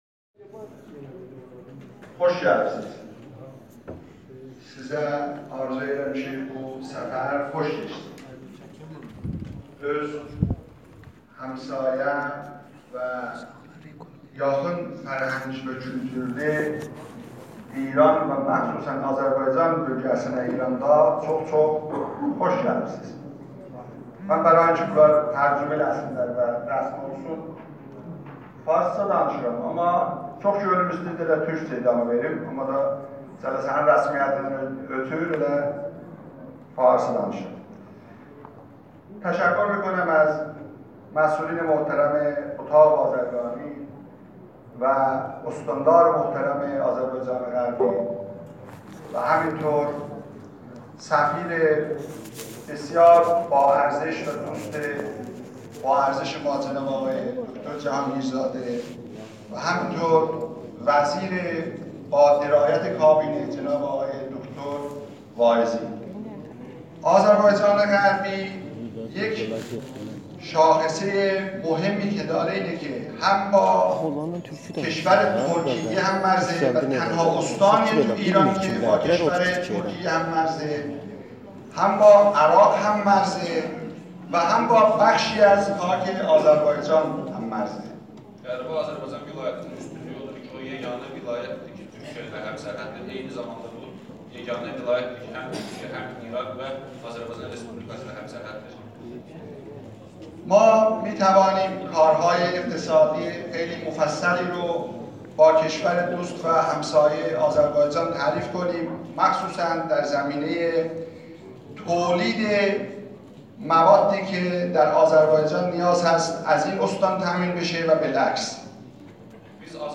فایل صوتی بدست آمده از سخنرانی سیدهادی بهادری در همایش تجاری ایران و آزربایجان شمالی